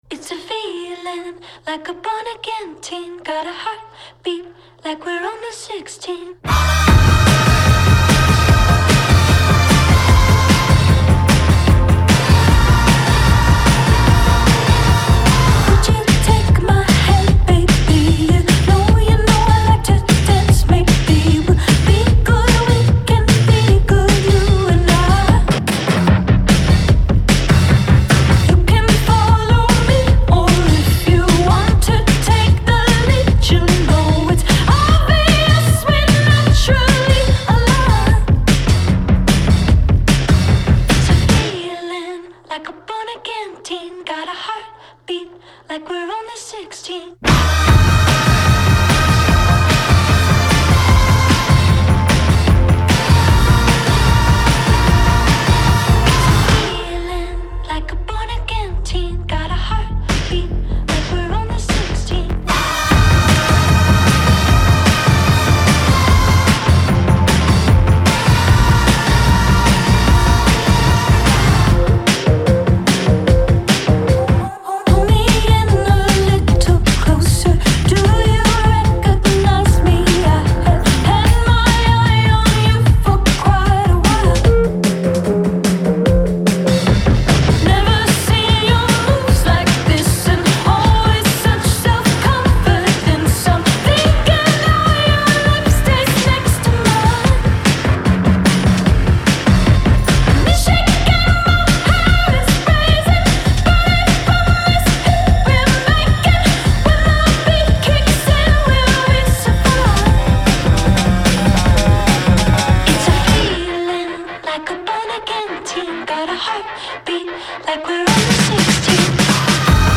with its sky-high chorus and dance-worthy beat